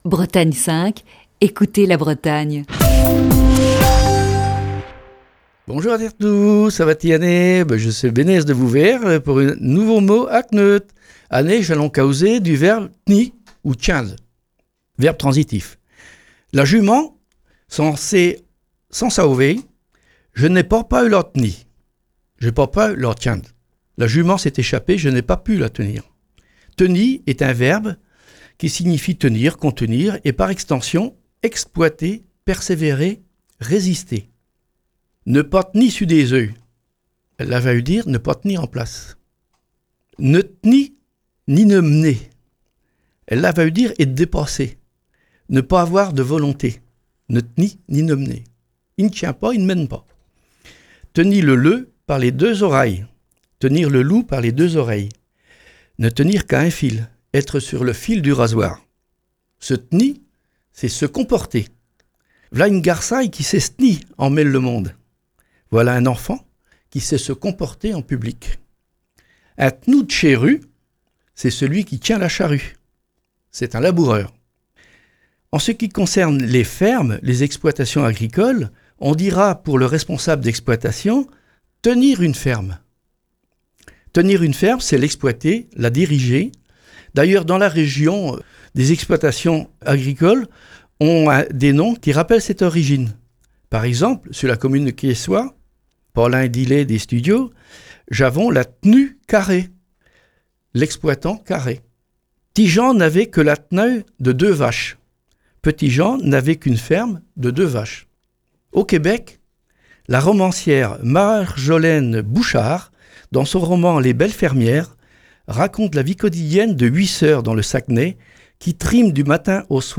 Chronique du 25 mars 2020.